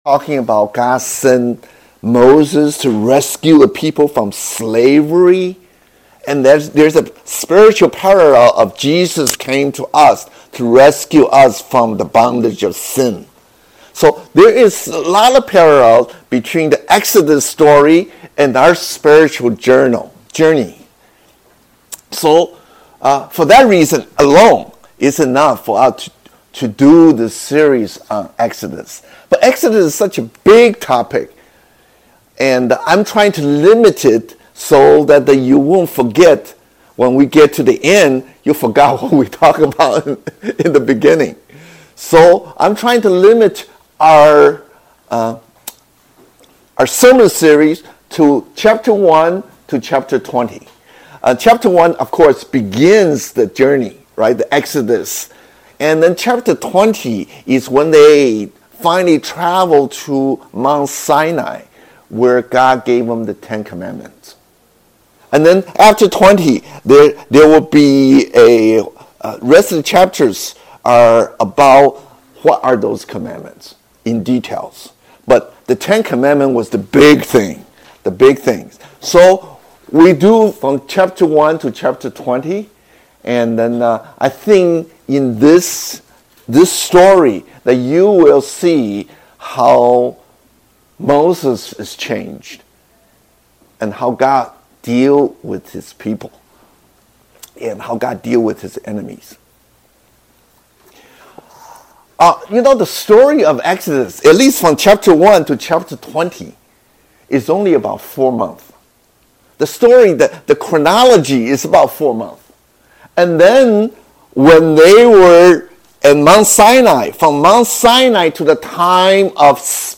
2023 Exodus-400 years in waiting 證道